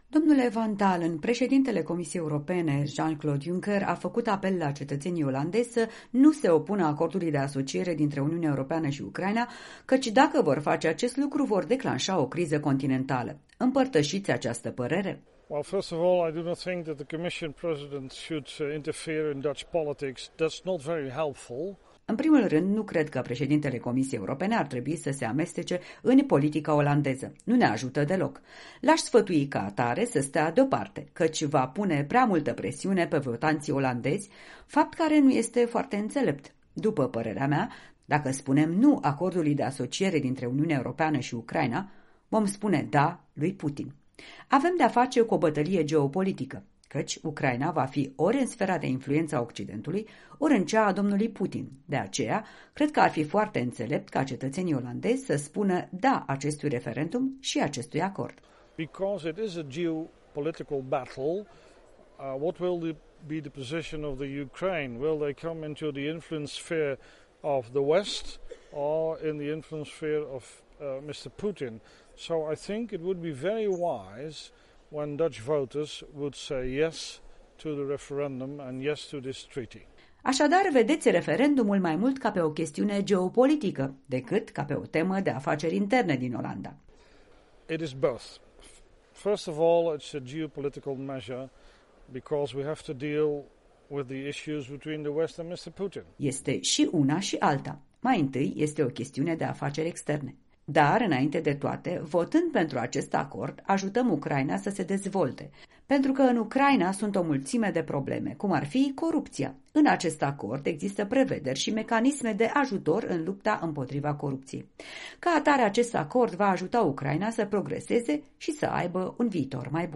De vorbă cu un eurodeputat olandez pe marginea unei campanii împotriva Acordului de Asociere UE-Ucraina.
Este campania impotriva Acordului de asociere cu Ucraina una pro-Putin sau, mai degraba, una împotriva UE, a fost una dintre întrebările pe care corespondenta noastră la Strasbourg i le-a adresat eurodeputatului olandez Peter van Dalen.